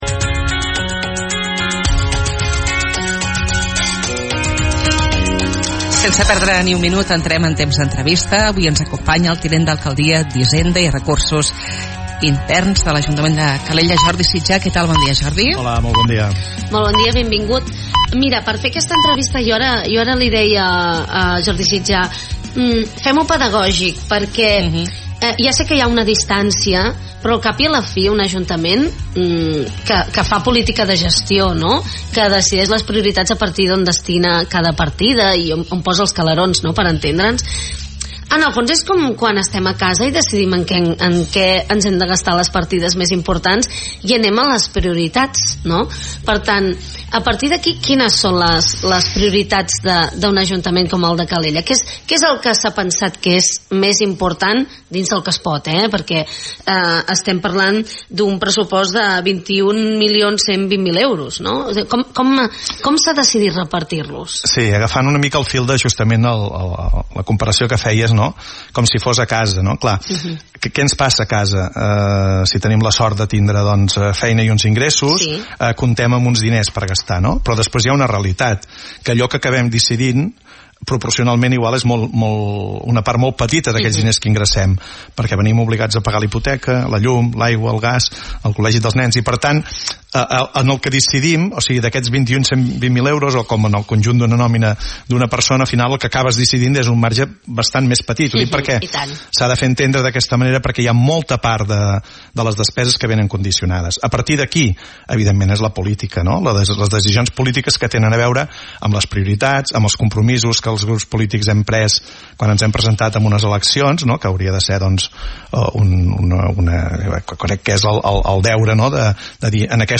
El Tinent d’alcaldia d’Hisenda i Recursos Interns, Jordi Sitjà, ens ha detallat al programa La Ciutat les línies més importants del Pressupost de l’Ajuntament de Calella 2018. Uns comptes aprovats ahir al Ple de l’Ajuntament, que segons afirma, prioritzen les polítiques socials, el manteniment de la ciutat i la promoció i projecció de Calella.
ENTREVISTA-SITJÀ-PRESSUPOST.mp3